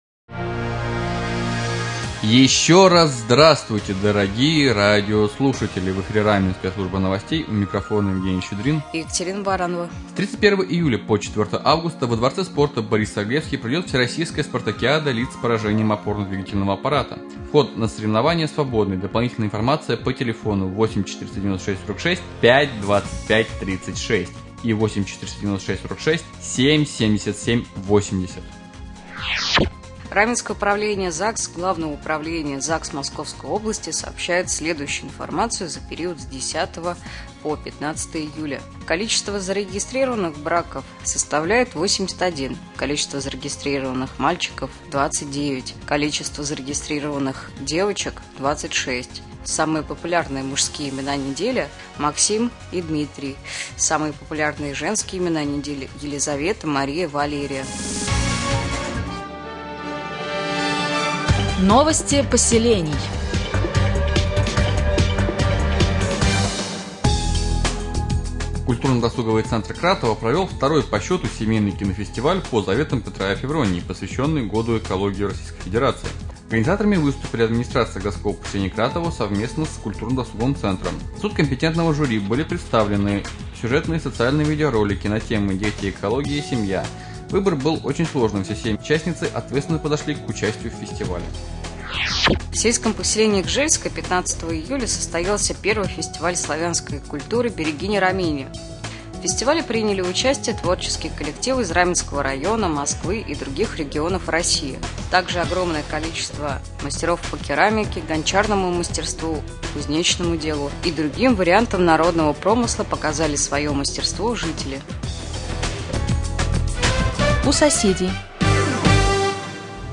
1. Новости
3. В студии побывали организаторы конкурса красоты «Мисс Раменское»